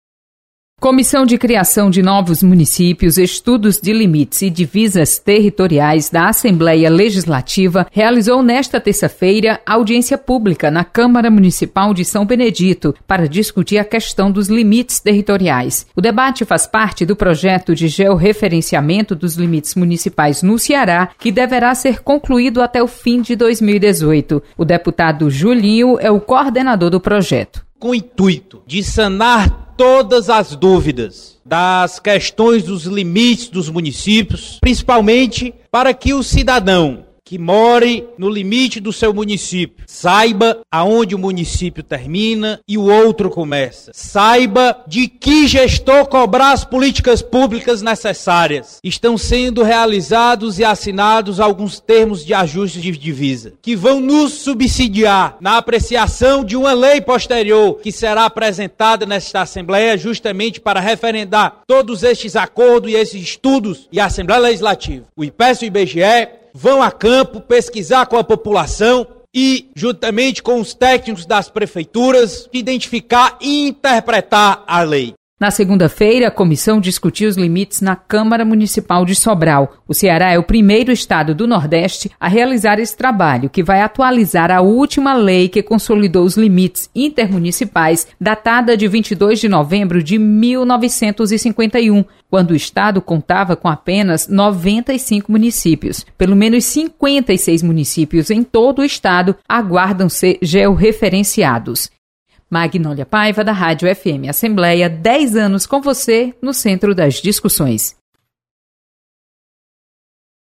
Você está aqui: Início Comunicação Rádio FM Assembleia Notícias Audiência Pública